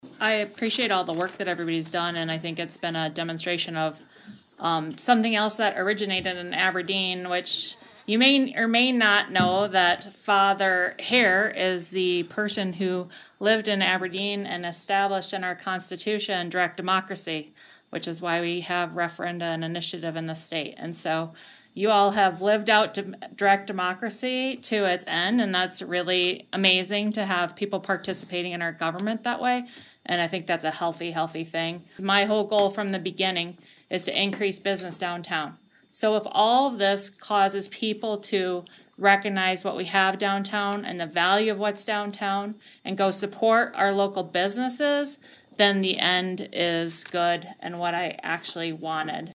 ABERDEEN, S.D (Hub City Radio)- At the Aberdeen, SD City Council meeting earlier this Monday evening, October 6th, the City Council voted unanimously on an 8-0 vote to keep downtown Main Street as a one-way road.
Councilwoman Erin Fouberg voted in favor to repeal but discuss the main goal for support was to bring more attention to downtown Aberdeen.